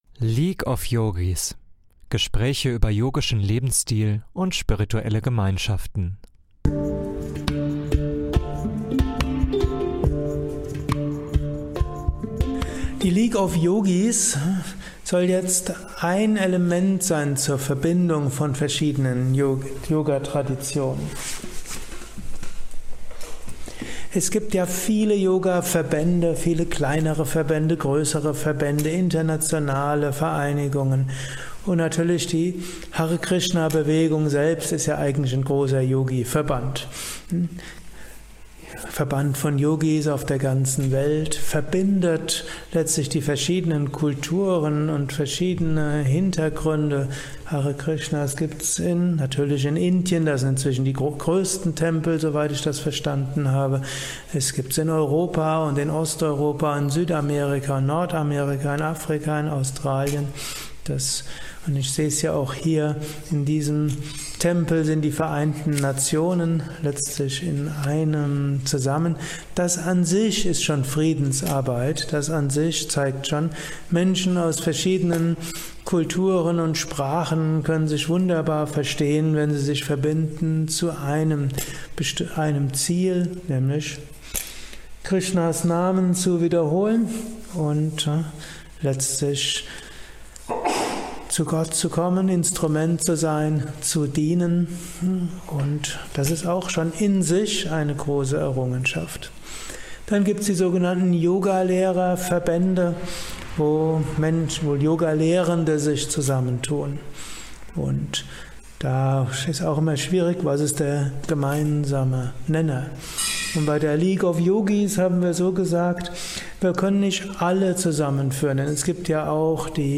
Ausschnitt aus einem Vortrag am 30.06.2020.